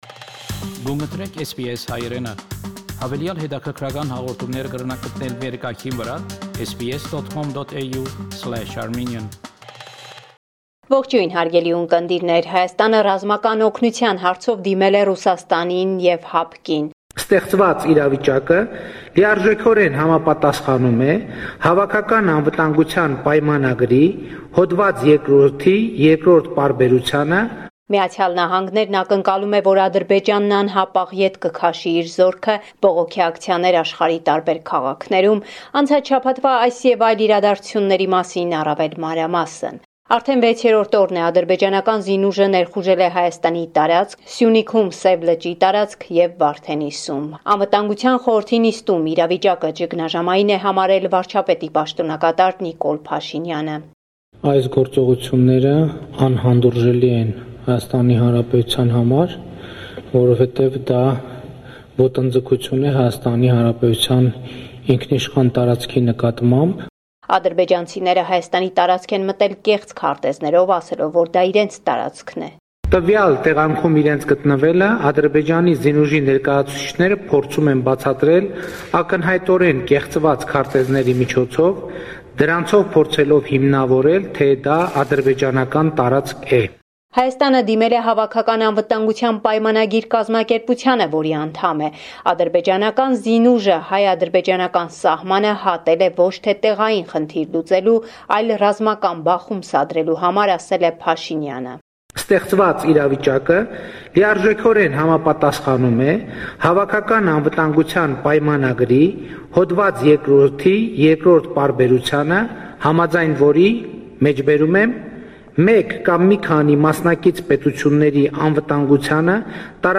Latest News from Armenia – 18 May 2021